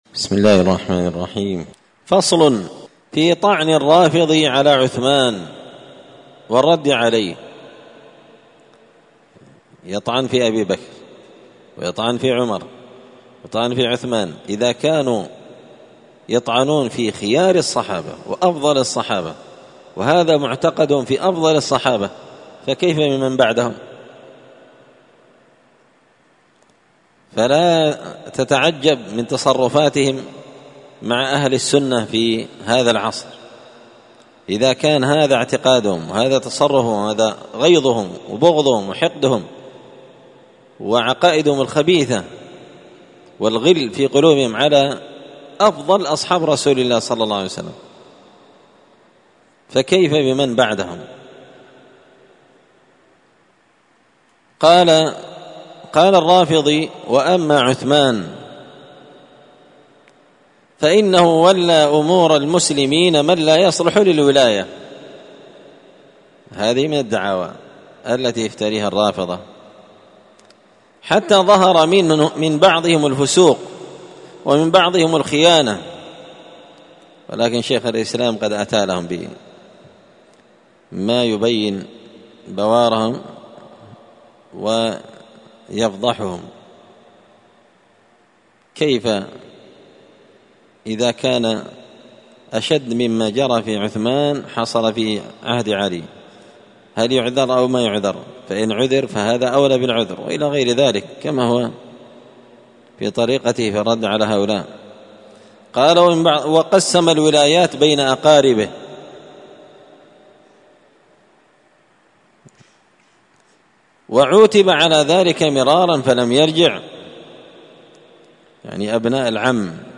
الخميس 18 ذو الحجة 1444 هــــ | الدروس، دروس الردود، مختصر منهاج السنة النبوية لشيخ الإسلام ابن تيمية | شارك بتعليقك | 9 المشاهدات
مسجد الفرقان قشن_المهرة_اليمن